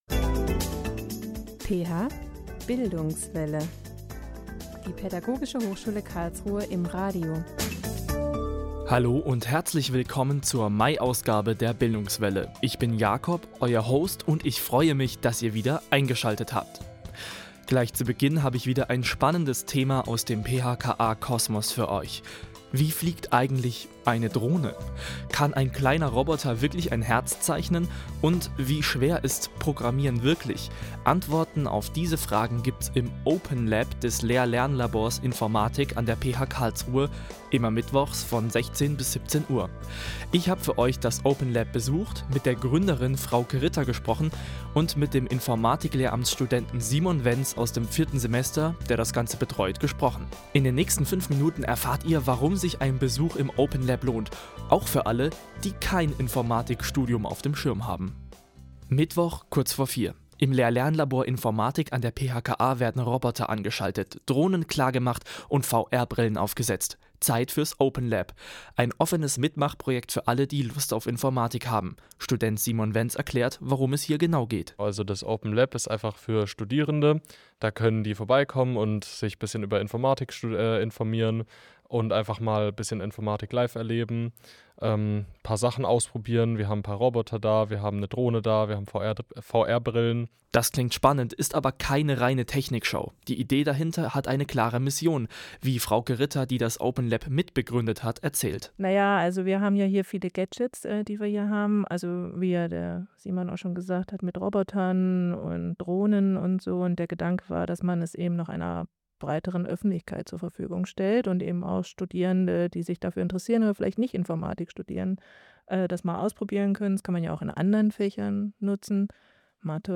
Open Lab und diskriminierungskritischer Unterricht / Interviews